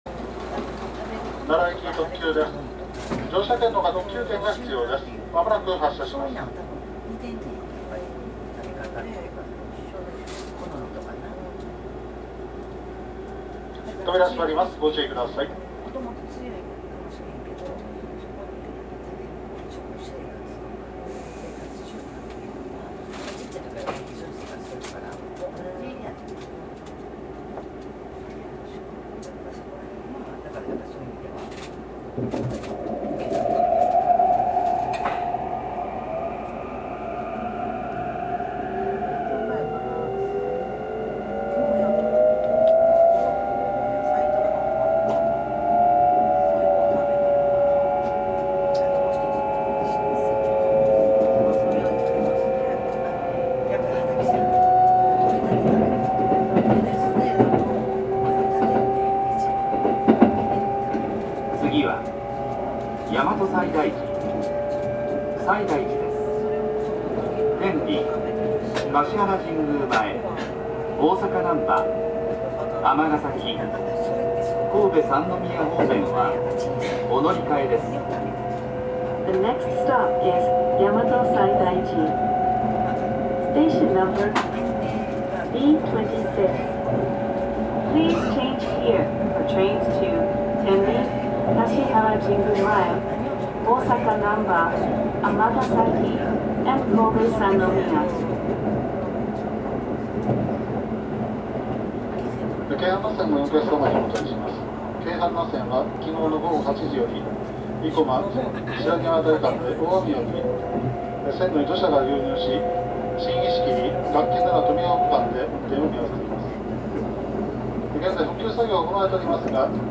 走行機器はIGBT素子によるVVVFインバータ制御で、定格230kWのMB-5097B形かご形三相誘導電動機を制御します。
走行音
録音区間：高の原～大和西大寺(特急)(お持ち帰り)